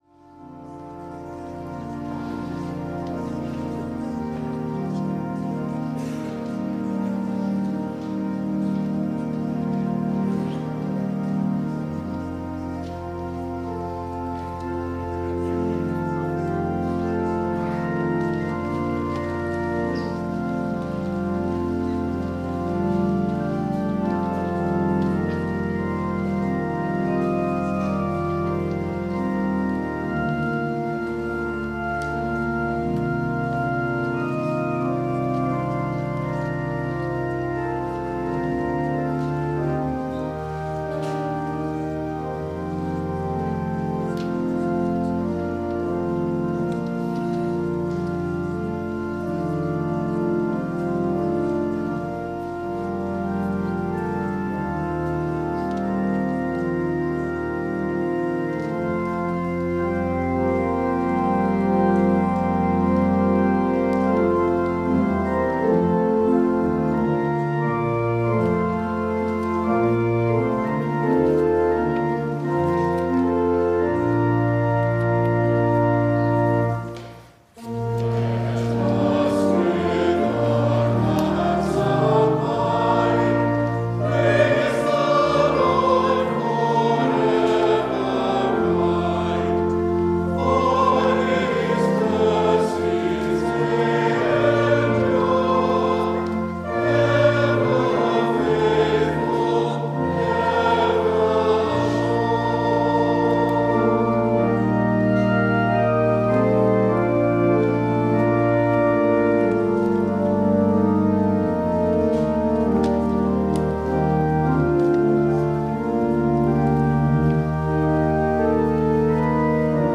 Worship and Sermon audio podcasts
WORSHIP - 10:30 a.m. Third after Epiphany